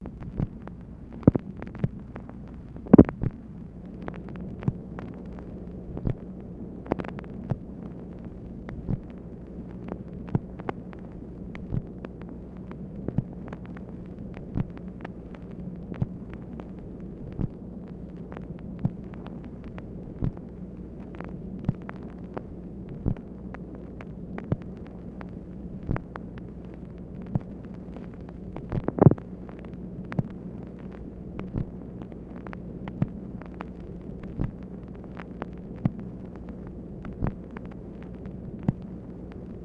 MACHINE NOISE, February 14, 1964
Secret White House Tapes | Lyndon B. Johnson Presidency